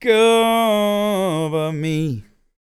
Blues Soul